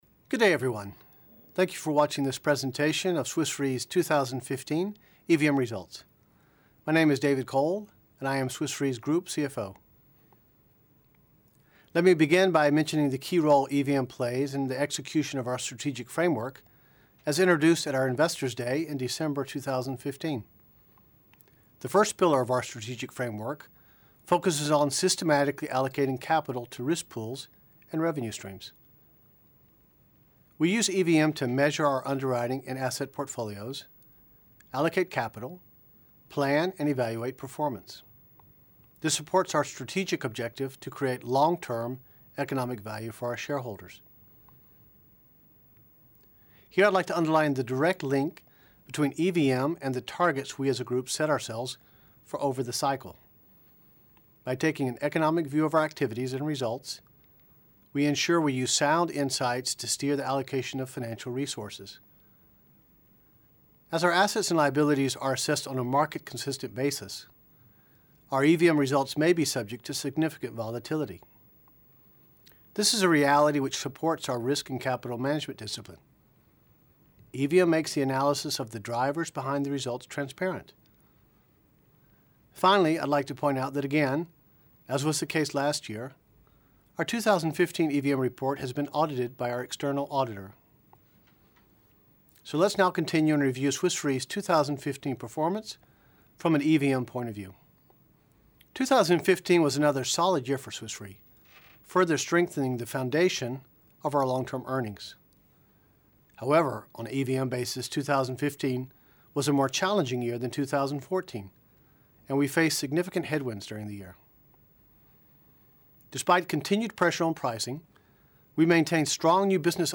Annual EVM results 2015 video presentation recording